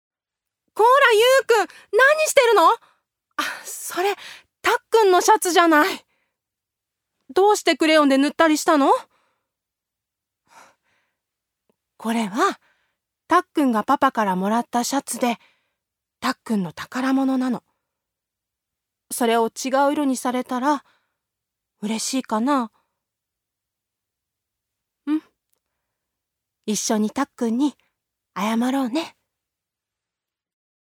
女性タレント
セリフ３